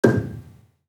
Gambang-G5-f.wav